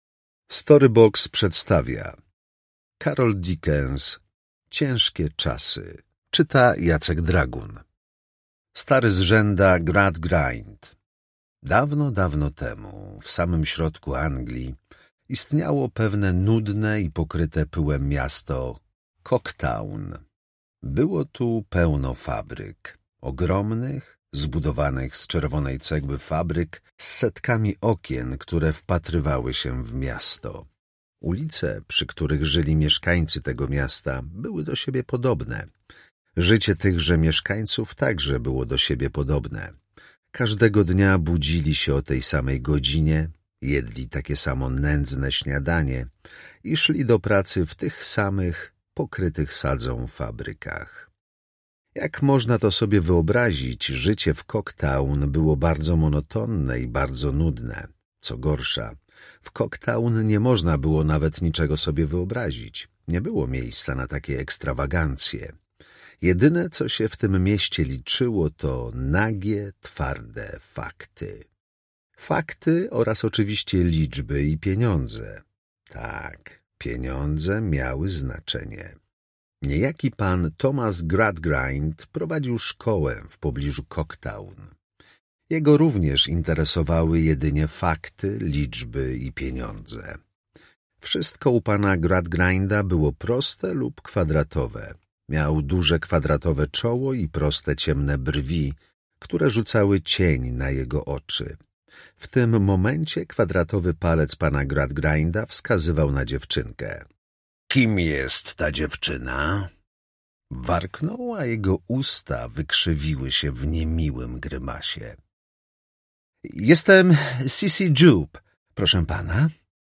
Klasyka dla dzieci. Charles Dickens. Tom 8. Ciężkie czasy - Charles Dickens - audiobook